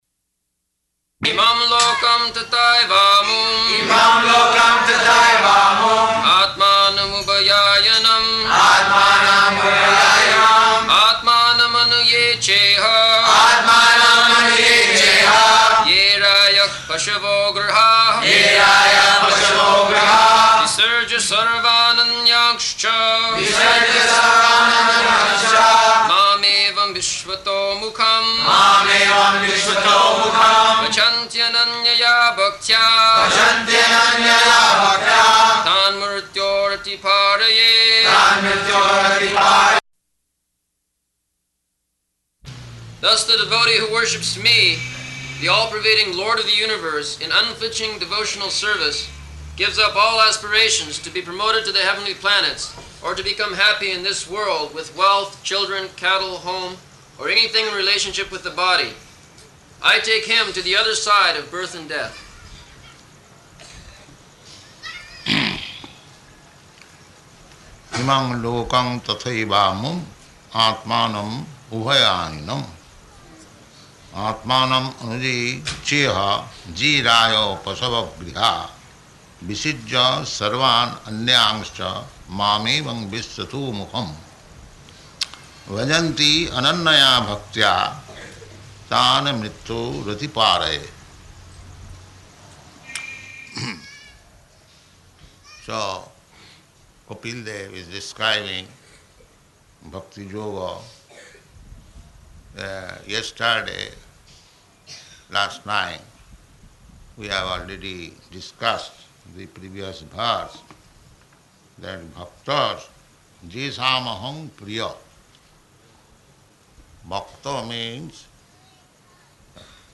Location: Bombay
[leads chanting of verse] [devotees repeat]